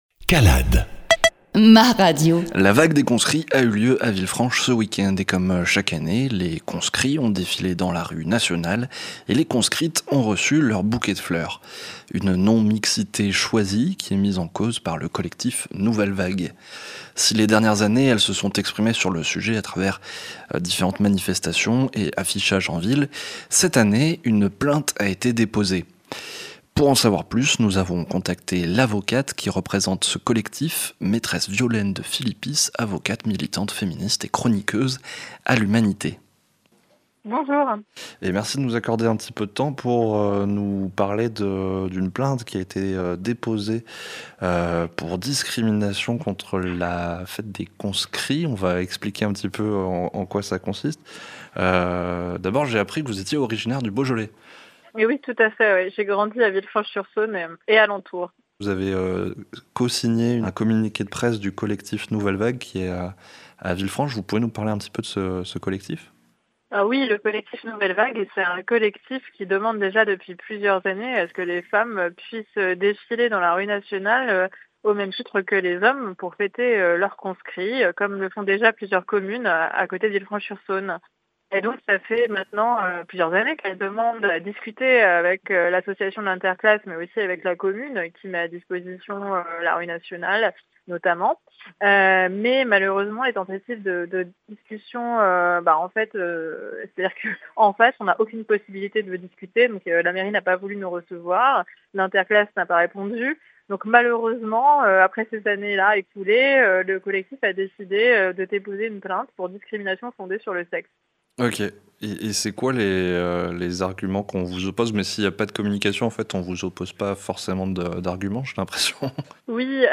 Reportage – 270125 Conscrits